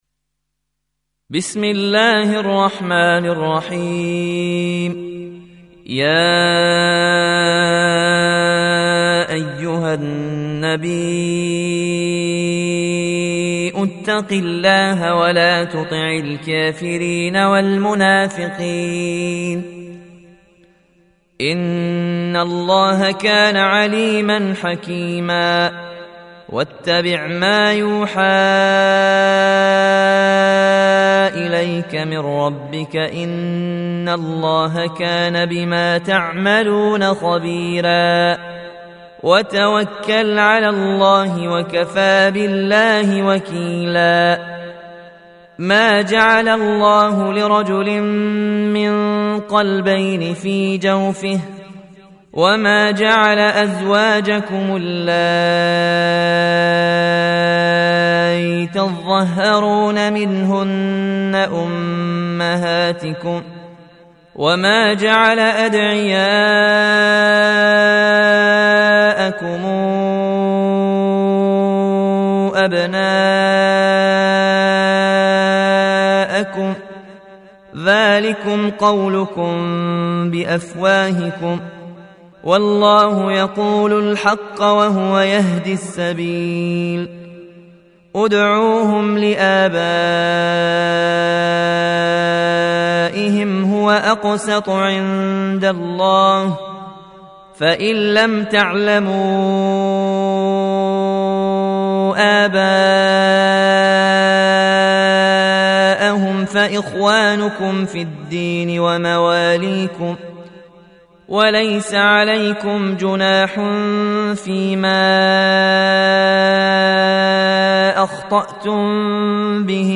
Audio Quran Tarteel Recitation
Surah Sequence تتابع السورة Download Surah حمّل السورة Reciting Murattalah Audio for 33. Surah Al�Ahz�b سورة الأحزاب N.B *Surah Includes Al-Basmalah Reciters Sequents تتابع التلاوات Reciters Repeats تكرار التلاوات